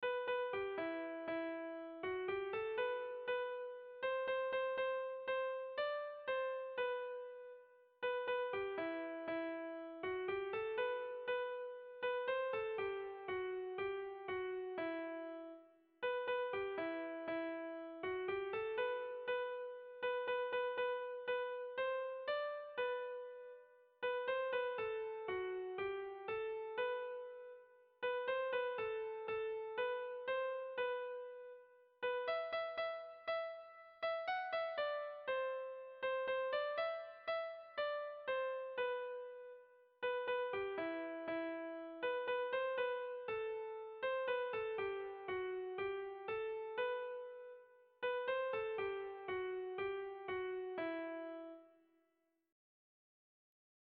Bertso melodies - View details   To know more about this section
Kontakizunezkoa
A-B-A-B-C-